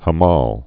(hə-mäl)